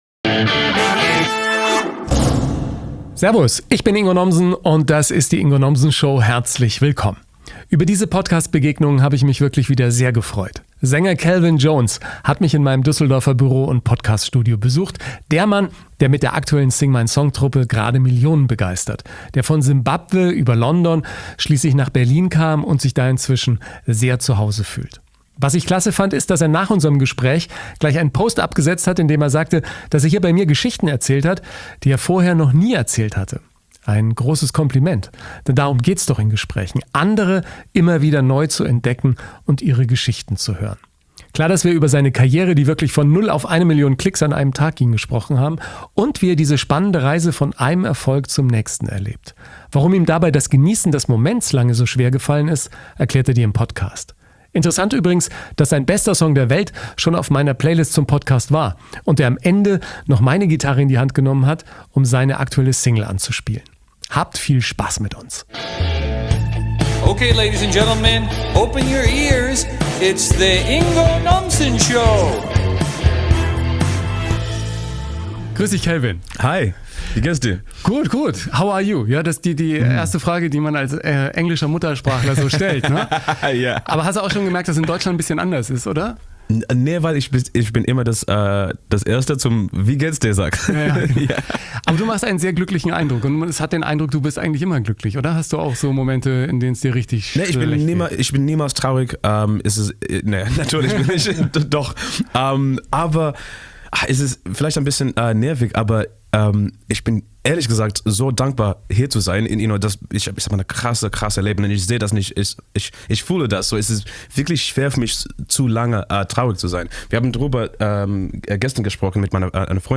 Sänger Kelvin Jones hat mich in meinem Düsseldorfer Podcast-Studio besucht.
Welchem Zufall er diesen Erfolg und den Start seiner spannende Reise zu verdanken hat, erklärt er Dir im Podcast. Wir sprechen auch darüber, warum ihm dabei das Genießen des Momentes lange so schwer gefallen ist. Interessant übrigens, dass sein „bester Song der Welt“ schon auf meiner Playlist zum Podcast war - und er am Ende noch meine Gitarre in die Hand genommen hat, um seine aktuelle Single anzuspielen!